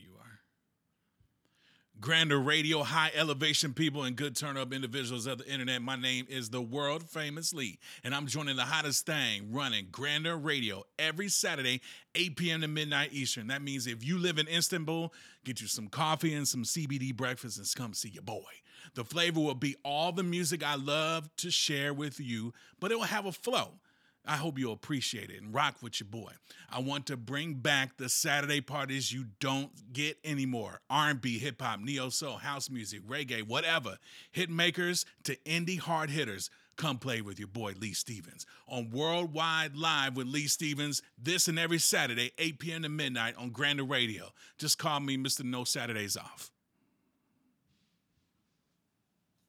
bringing back the house party-style music sets.